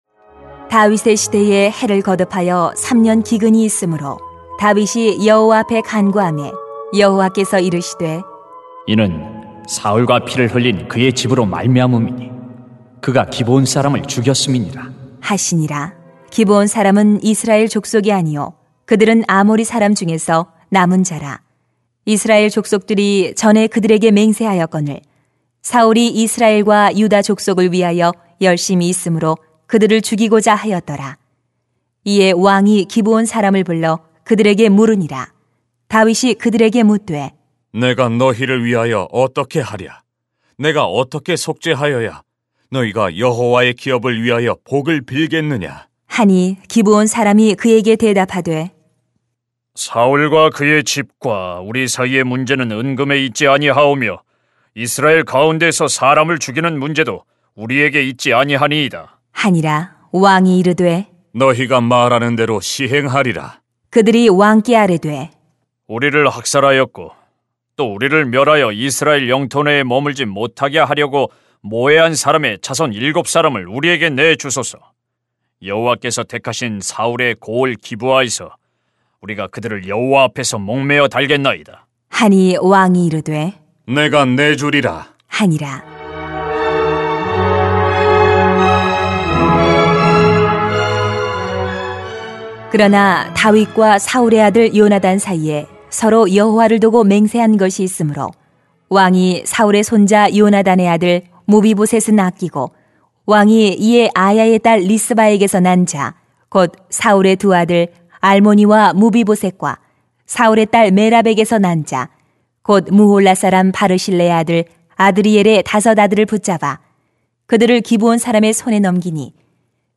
[삼하 21:1-14] 원통함을 풀어야 합니다 > 새벽기도회 | 전주제자교회